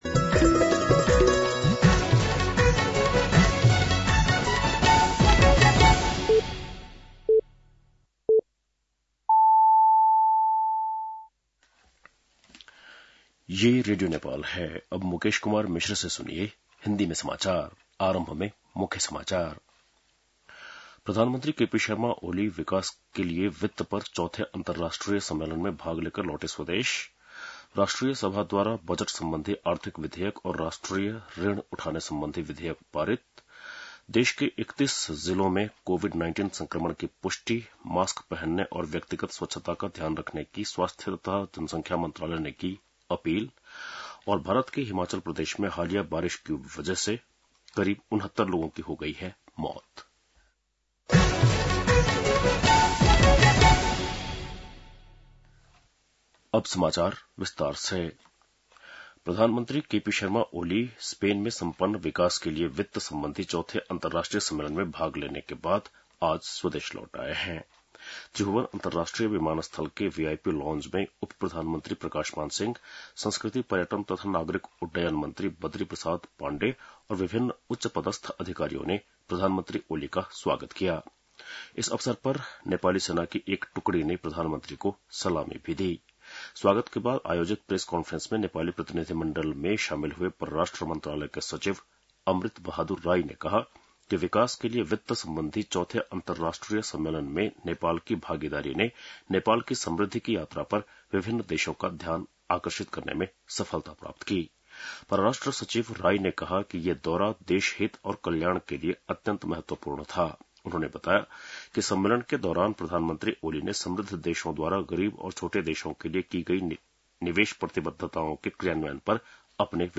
बेलुकी १० बजेको हिन्दी समाचार : २० असार , २०८२